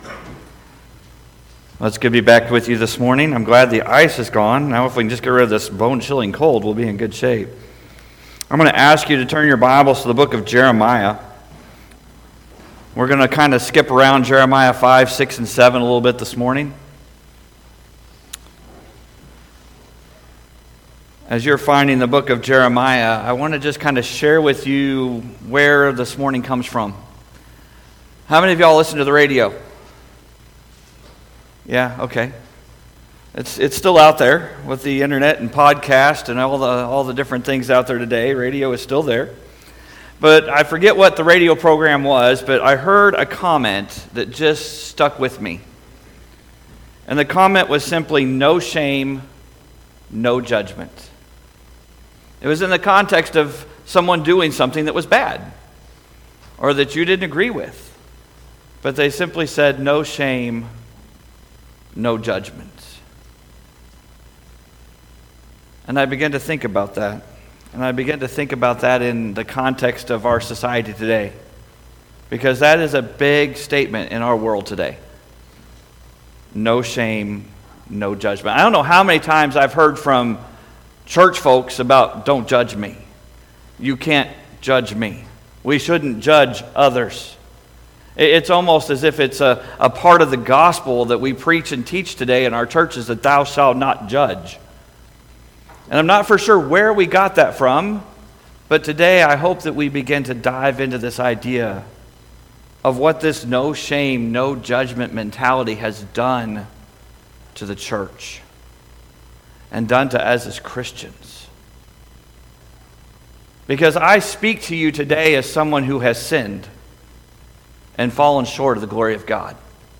Sunday-Service-1-19-25.mp3